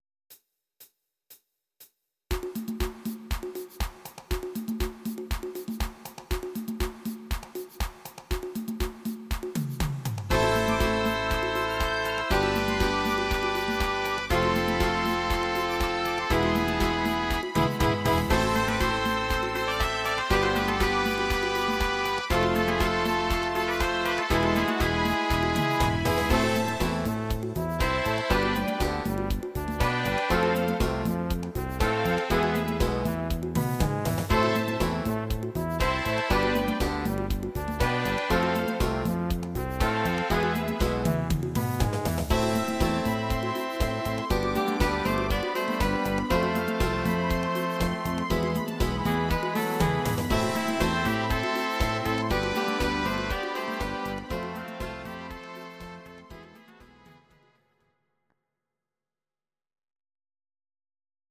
instr. Orchester